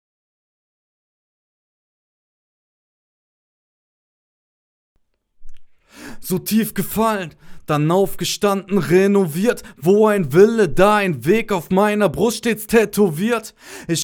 Aufnahme zu Dumpf?
Hallo liebe Mitglieder, ich habe das Problem das meine Aufnahmen für mich subjektiv einfach viel zu dumpf klingen.
Hörbeispiel (komplett roh) ist angehängt Aufnahmekette ist wie folgt: Neumann TLM102 -> Golden Age MKIII Plus -> Steinberg UR22 (nur als A/D-Wandler/Interface) ->PC Der Golden Age ist eingestellt mit Gain: 50 und Output knapp über halb.